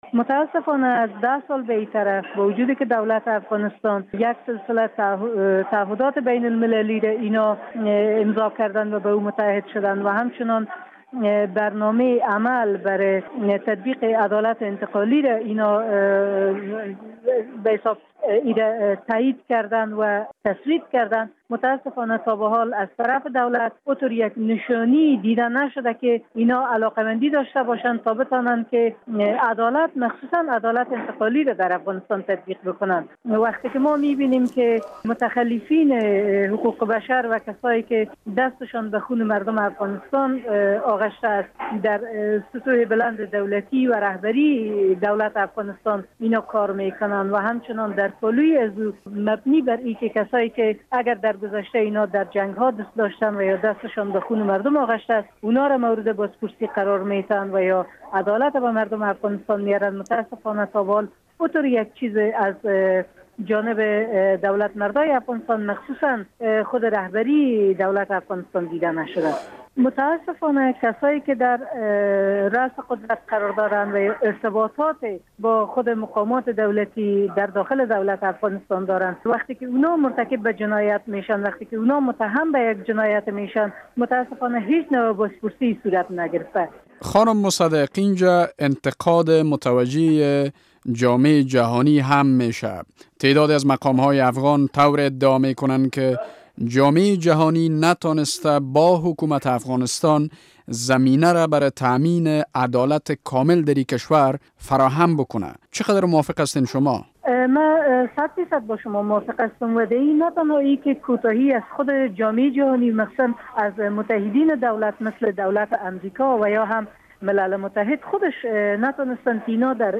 مصاحبه با نمایندهء سازمان عفو بین الملل در مورد اظهارات سیما سمر